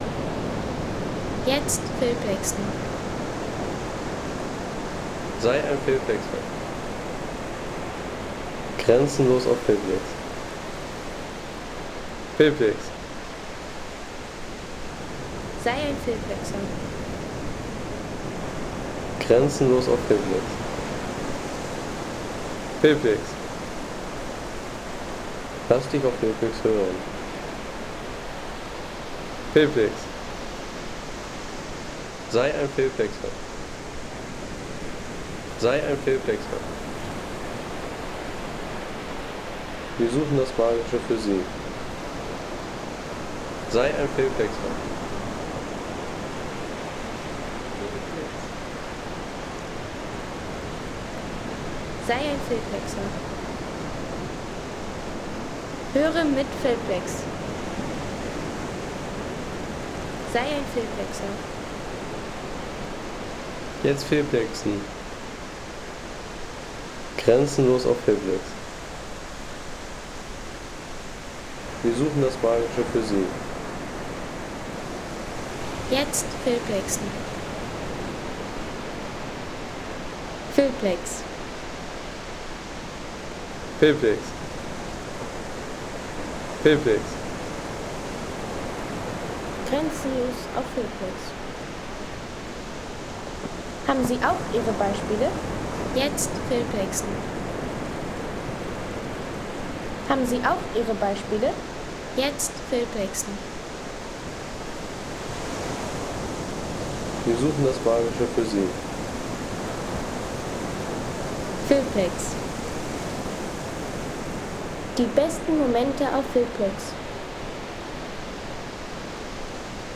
Wellenrauschen am Playa de las Burras | Feelplex
Ein Strandsound, der weiß, wie Entspannung klingt
Sanftes Wellenrauschen vom Playa de las Burras auf Gran Canaria.
Sanfte, lang auslaufende Wellen vom Playa de las Burras auf Gran Canaria. Eine natürliche Strandkulisse für Film, Postkarten und Hintergrundszenen.